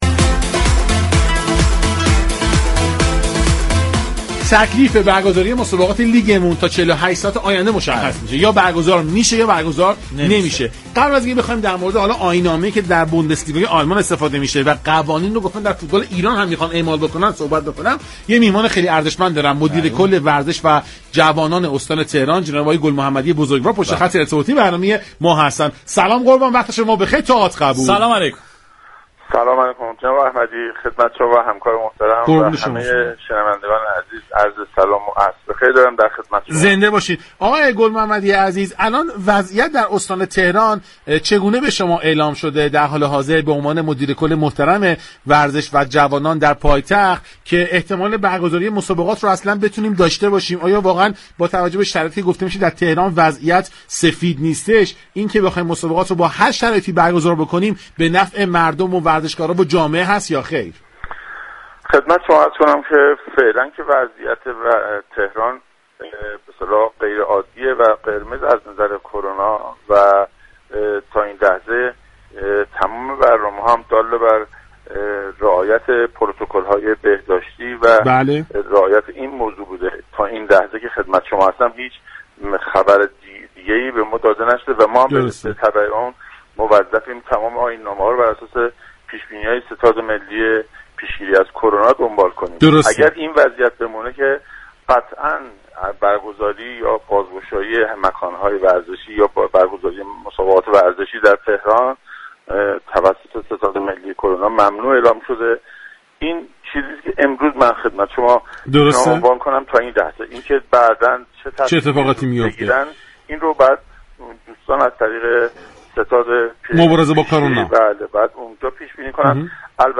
مدیركل ورزش و جوانان استان تهران در پایان این گفتگوی رادیویی افزود: فكر می‌كنم عده‌ای مایل هستند كه پشت درهای بسته و بدون حضور تماشاگر مسابقات ورزشی نظیر فوتبال را برگزار كنند اما بنده به هیچ عنوان با این مساله موافق نیستم و حفظ سلامت ورزشكاران بسیار مهم‌تر از این است كه به هر دلیلی بخواهیم مسابقات را برگزار كنیم.